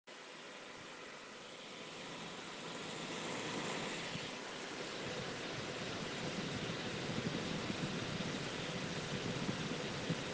Play Som Estranho Da Placa De Video - SoundBoardGuy
Play, download and share Som estranho da placa de video original sound button!!!!